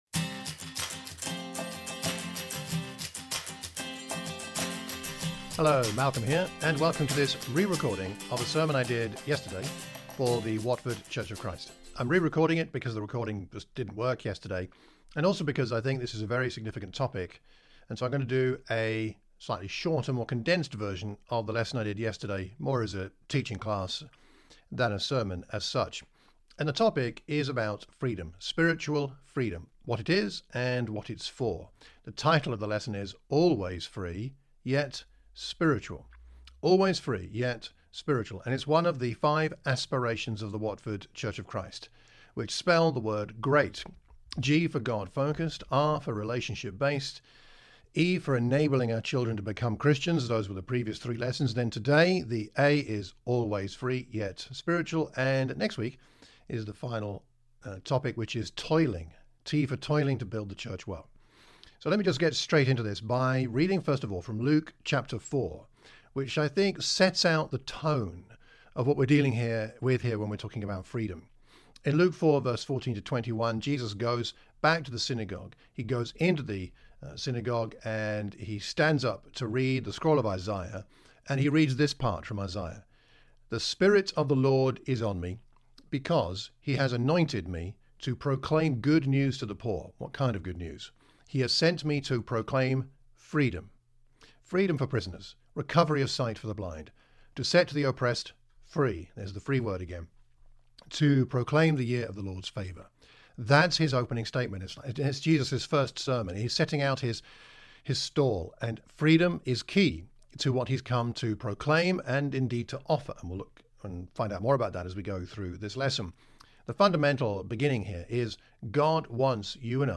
For the time being, I will concern myself with putting my sermon outline below along with the Scriptures referenced.